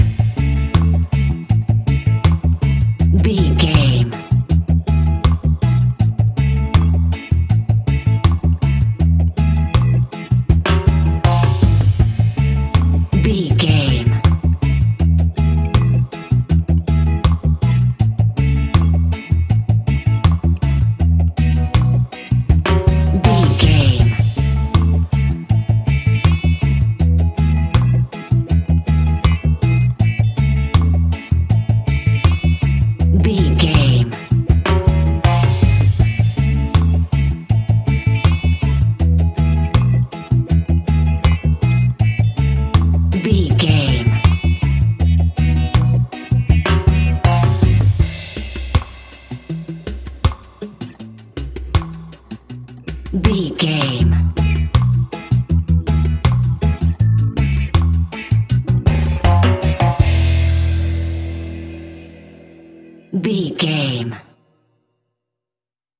Classic reggae music with that skank bounce reggae feeling.
Ionian/Major
laid back
chilled
off beat
drums
skank guitar
hammond organ
percussion
horns